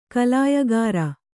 ♪ kalāyagāra